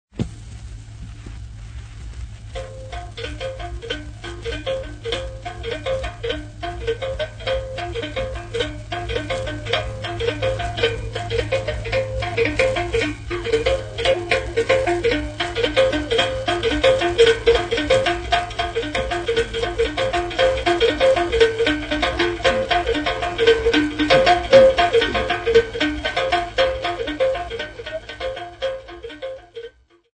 Folk Music
Field recordings
Africa Mozambique city not specified f-mz
sound recording-musical
Indigenous music